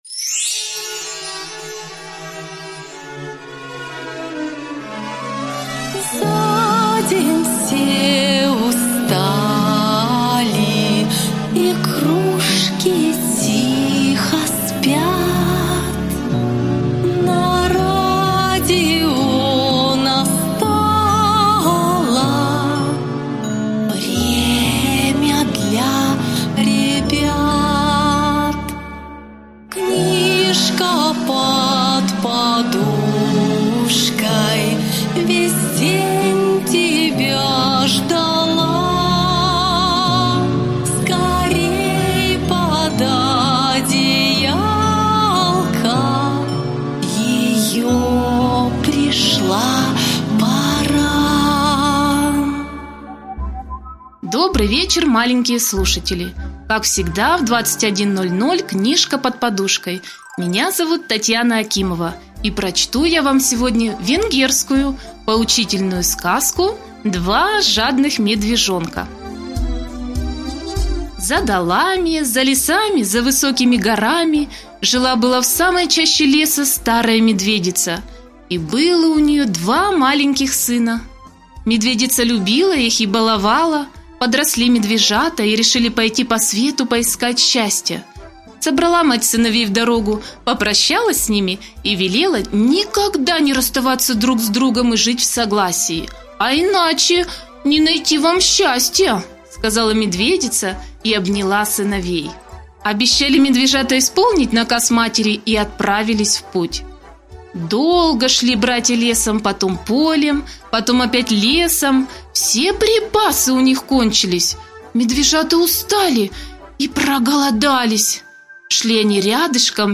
Два жадных медвежонка - венгерская аудиосказка - слушать онлайн